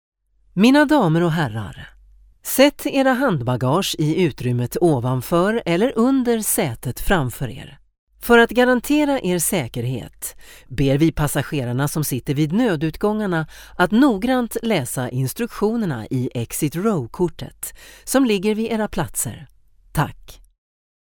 Swedish female voice over